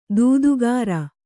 ♪ dūdugāra